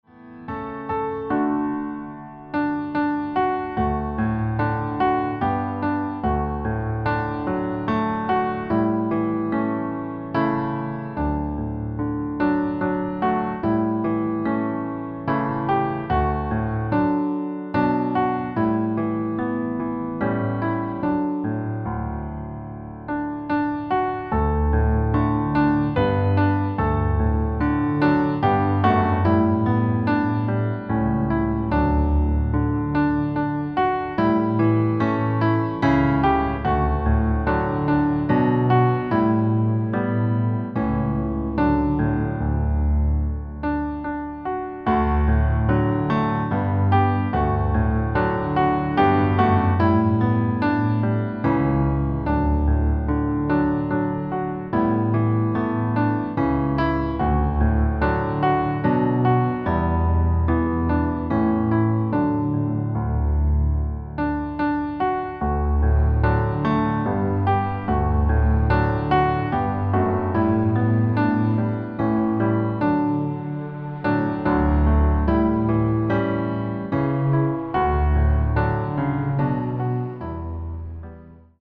• Tonart: Bb Dur, C Dur , D Dur (Originaltonart )
• Art: Klavierversion mit Streichern
• Das Instrumental beinhaltet NICHT die Leadstimme